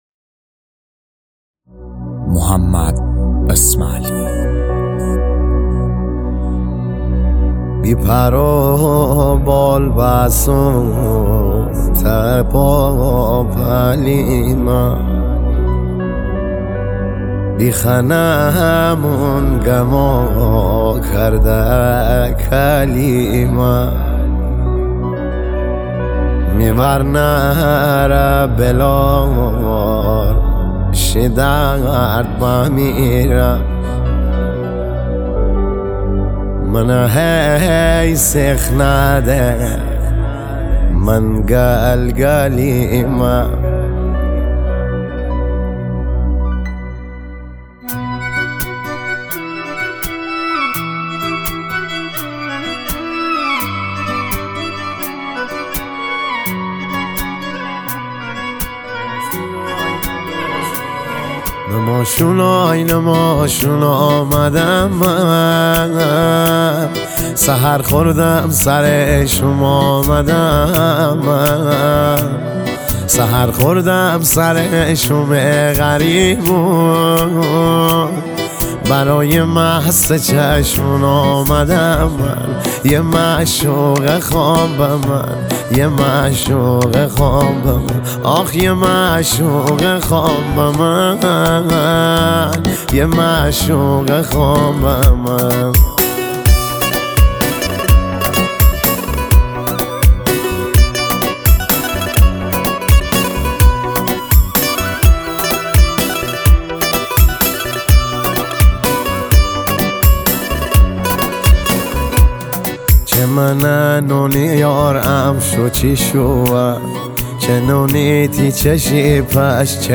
ریتمیک ( تکدست )
دانلود آهنگ های مازندرانی جدید